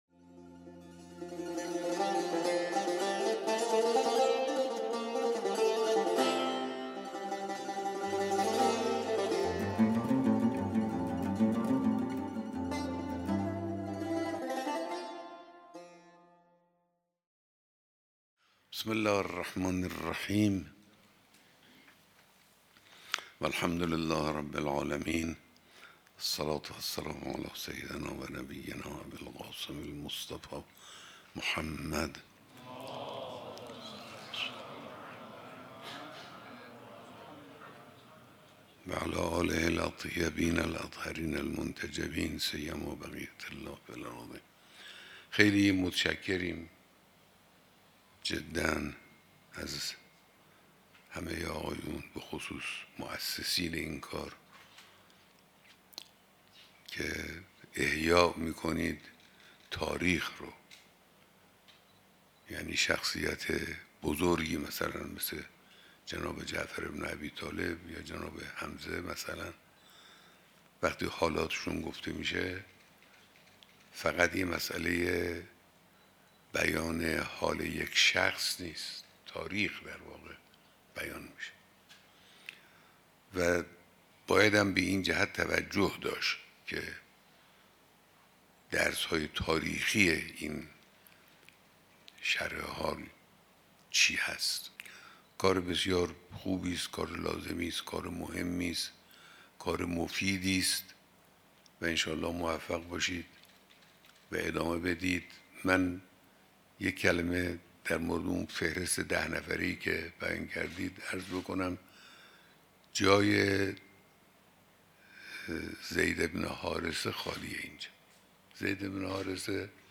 بیانات در دیدار دست‌اندرکاران همایش بین‌المللی جناب «جعفر بن ابیطالب»